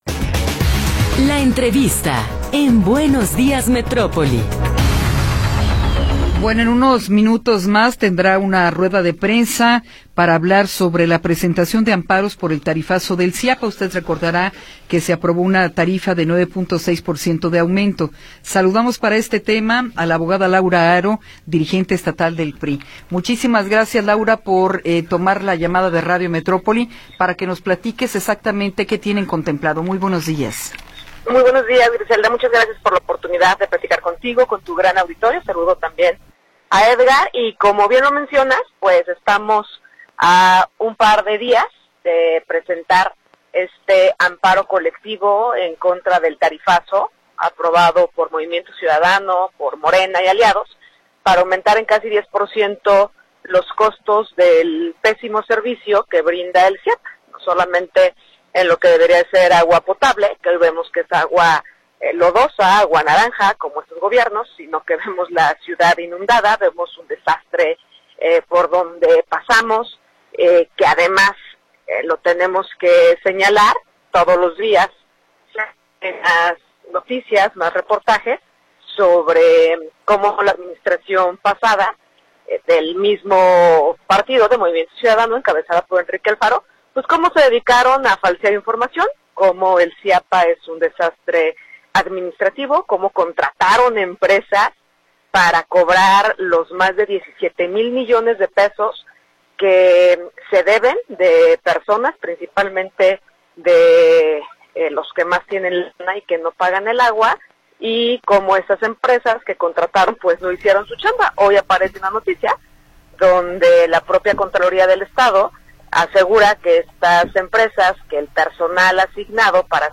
Entrevista con Laura Haro Ramírez